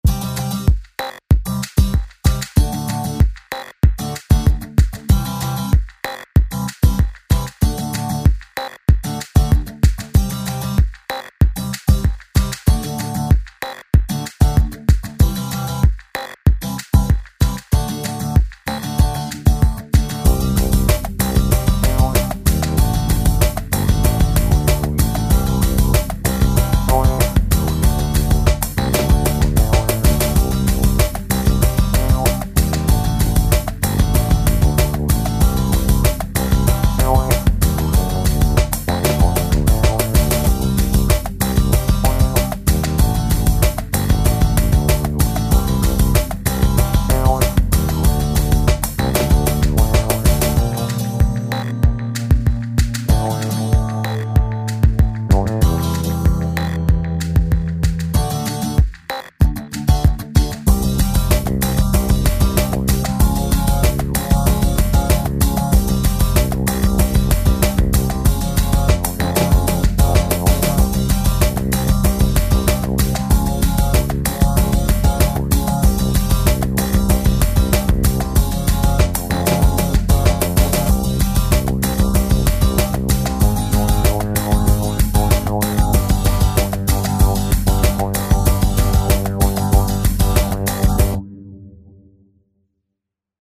demo-5演示了三连音的使用。
鼓来自Live5中现成的clip，Dubphonic-95-F Groove和Braintease-125-Groove。
贝斯来自Scarbee j-finger。
拍速95。